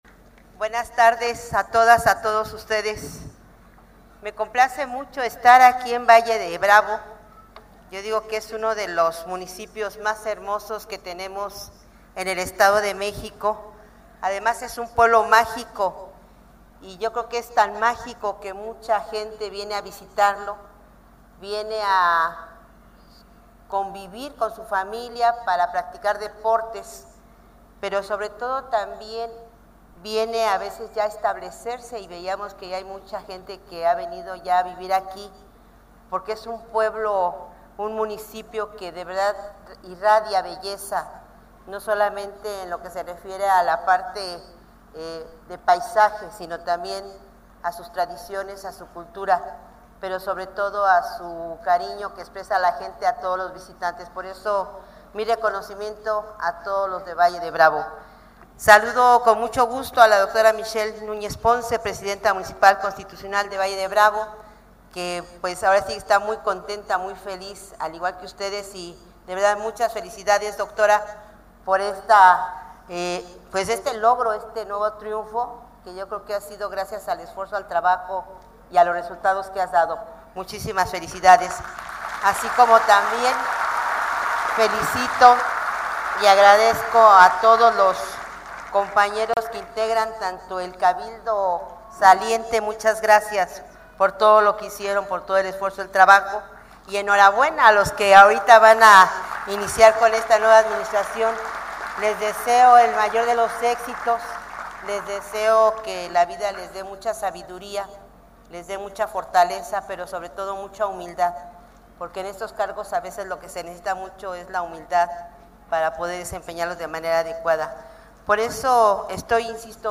La Gobernadora Delfina Gómez toma protesta a Michelle Núñez Ponce, como Presidenta Municipal de Valle de Bravo. Destaca los avances en programas de bienestar, obra pública, salud y educación en beneficio del pueblo vallesano.
AUDIO-MENSAJE-COMPLETO_DGA_TOMA-DE-PROTESTA-VALLE-DE-BRAVO.mp3